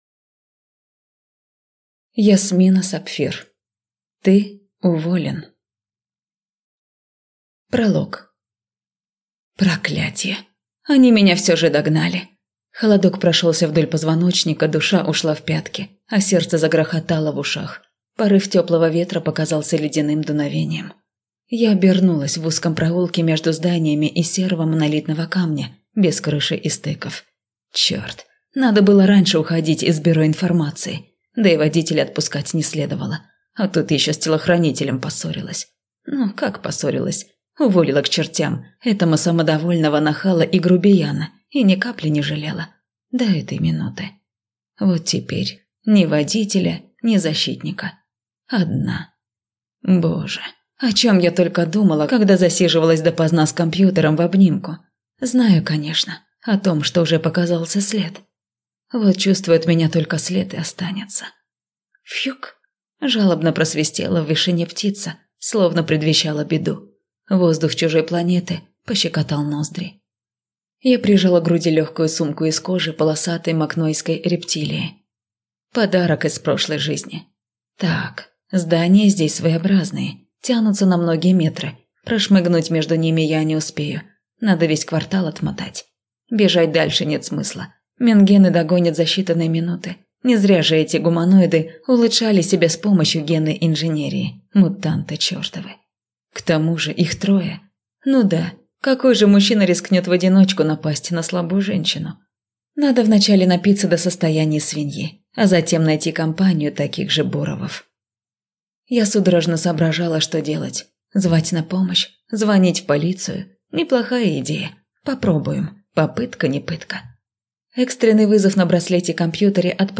Аудиокнига Ты уволен!